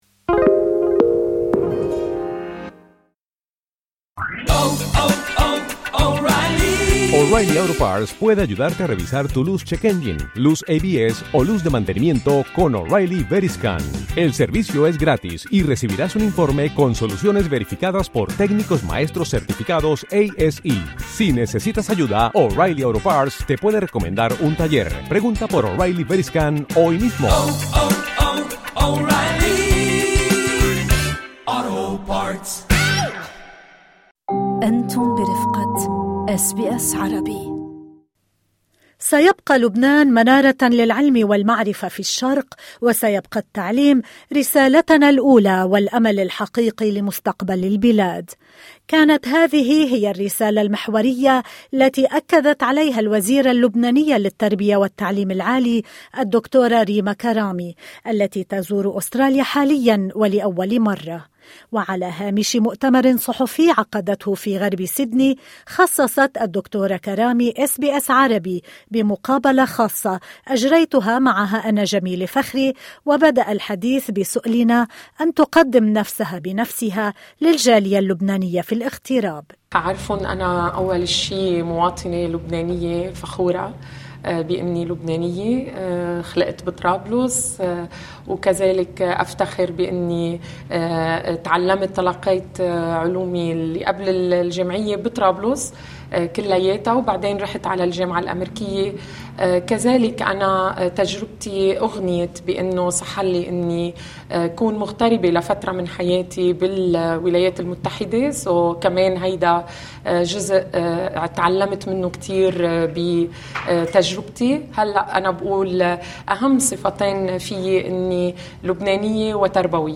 Lebanese Minister of Education and Higher Education, Dr. Rima Karami